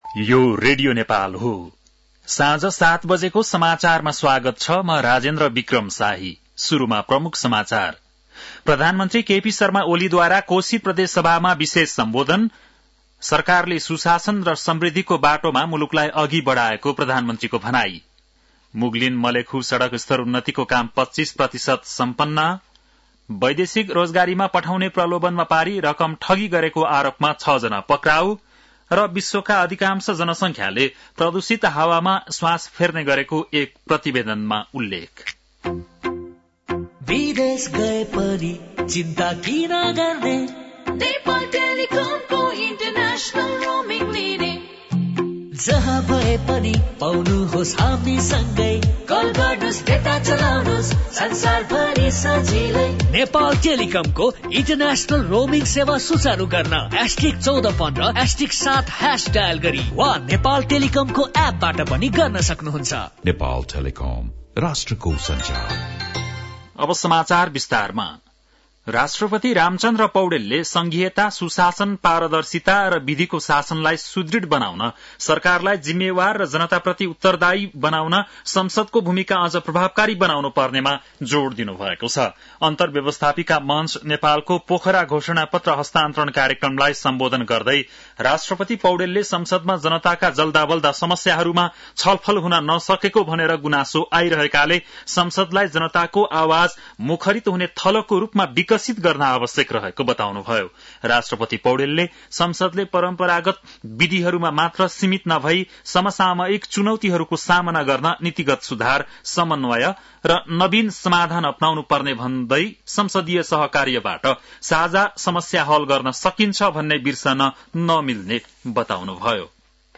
बेलुकी ७ बजेको नेपाली समाचार : २८ फागुन , २०८१